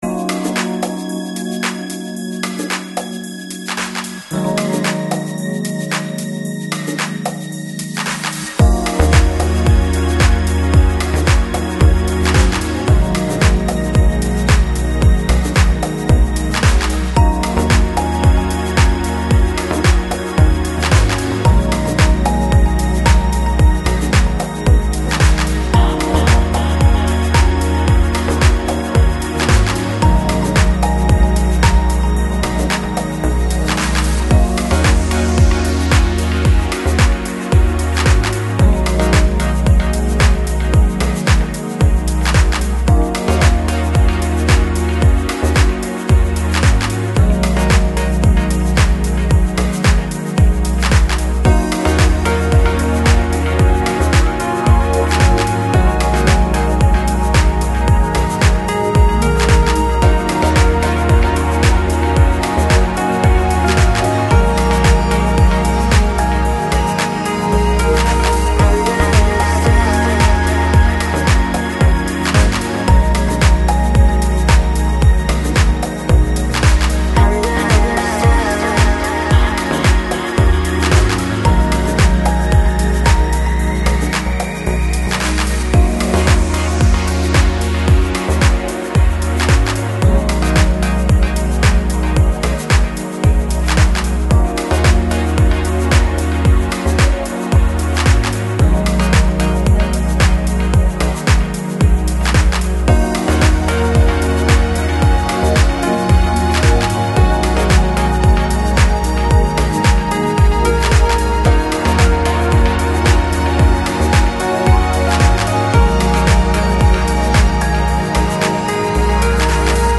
Жанр: Lounge, Chill Out